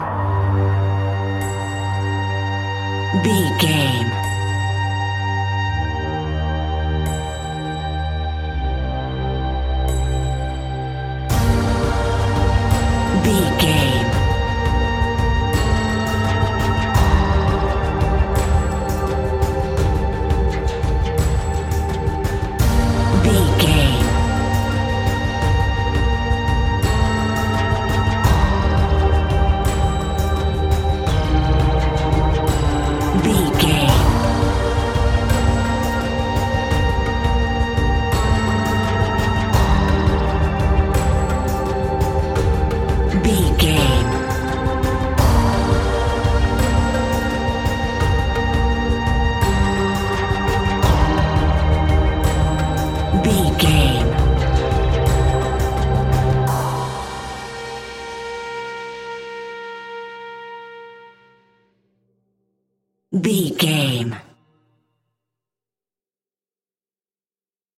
Aeolian/Minor
ominous
dark
haunting
eerie
synthesizer
drum machine
electronic music
electronic instrumentals
Horror Synths